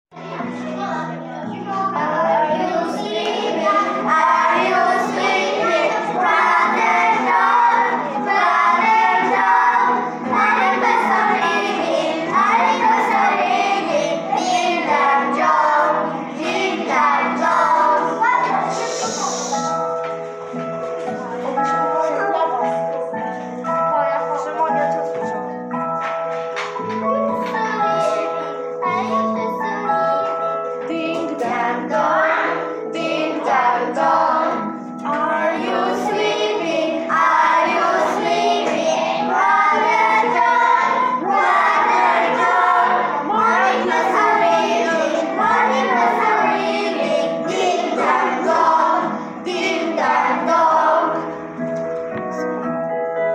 Lekcje w klasach 2 i 3 z okazji Europejskiego Dnia Języków
Ponadto, niektórzy uczniowie przygotowali i zaśpiewali piosenkę pt. „Are you sleeping” w języku angielskim.
Piosenka "Are you sleeping" w wykonaniu klasy 3a [890.22 kB]